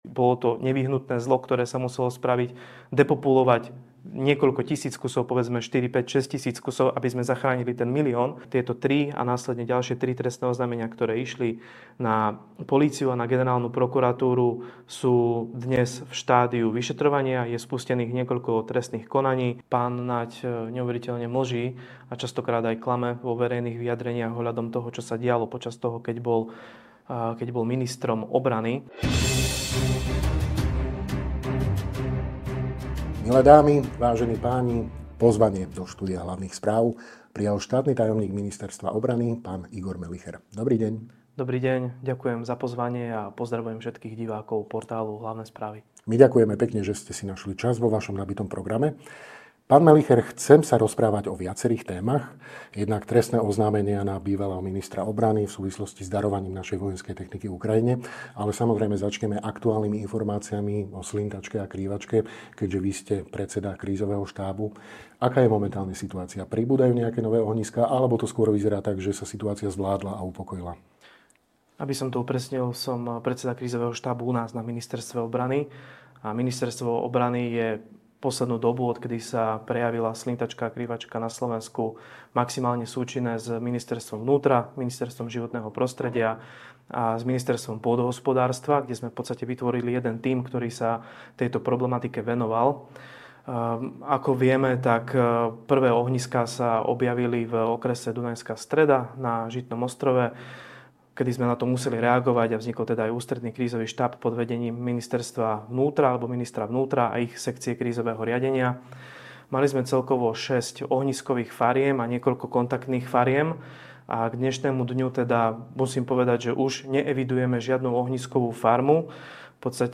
Rozprávali sme sa so štátnym tajomníkom Ministerstva obrany SR, Mgr. Igorom Melicherom.